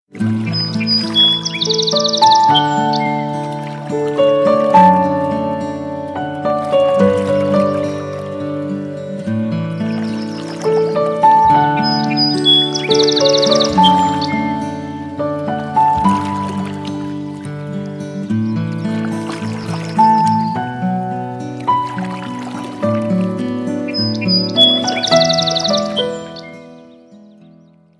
Теги: Спокойный, птички, природа, Будильник, вода, утро